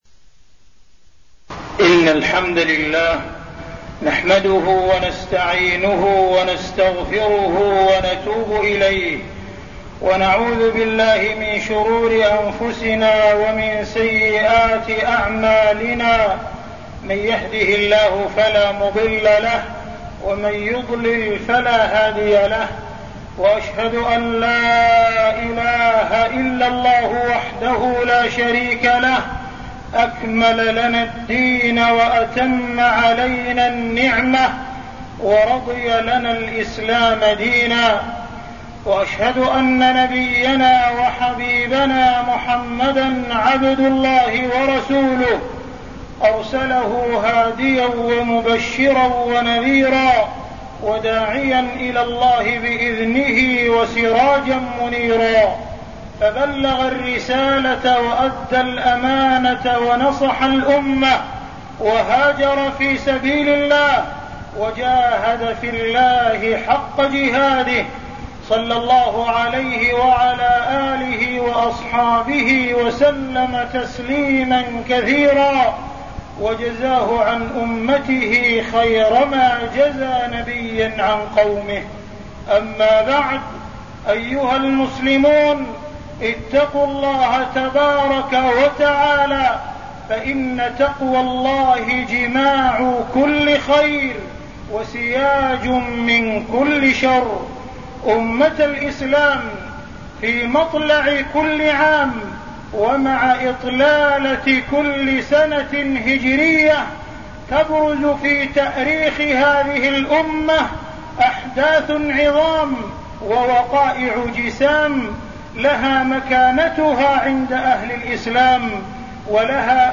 تاريخ النشر ٨ محرم ١٤١٥ هـ المكان: المسجد الحرام الشيخ: معالي الشيخ أ.د. عبدالرحمن بن عبدالعزيز السديس معالي الشيخ أ.د. عبدالرحمن بن عبدالعزيز السديس استقبال العام الهجري الجديد